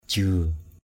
/ʤiø:˨˩/ (d.) thuế = impôt. hakak jiâ hkK j`;% đóng thuế = verser les impôts. rik jiâ r{K j`;% thu thuế = recueillir l’impôt. ba padai nao hakak jiâ b%...